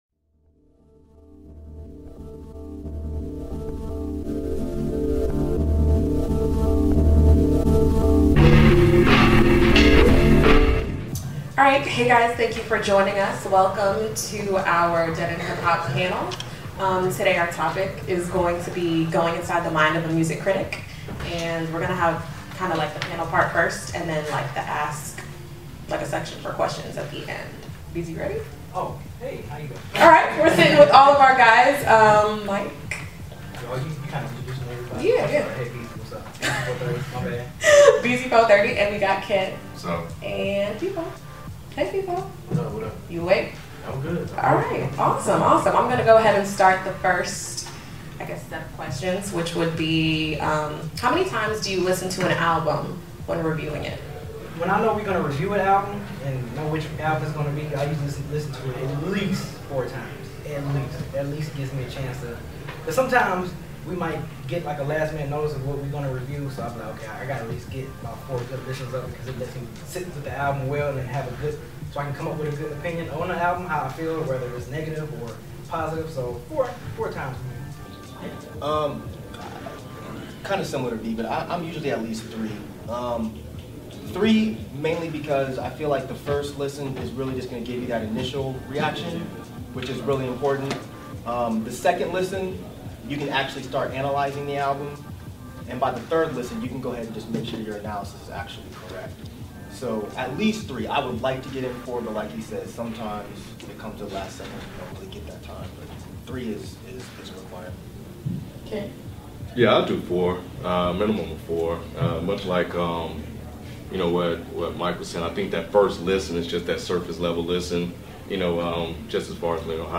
Inside the Mind of a Music Critic | DEHH Live Panel Discussion